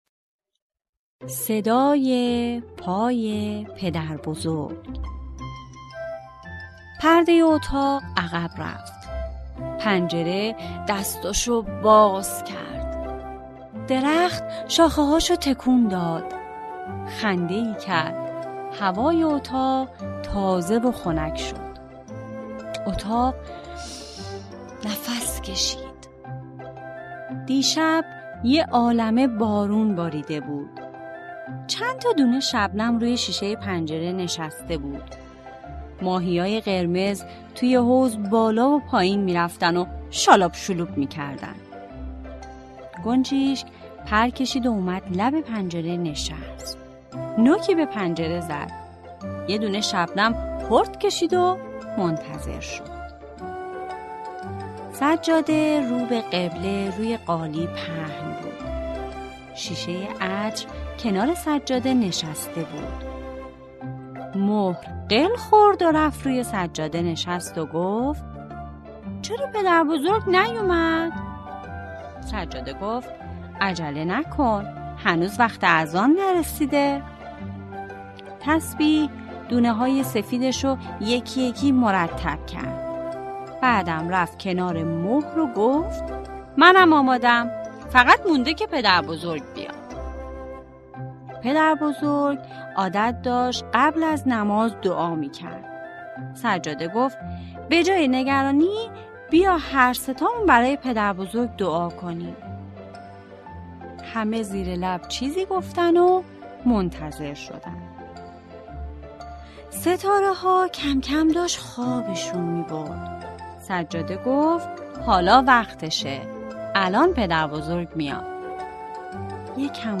قصه های کودکانه؛ صدای پای پدر بزرگ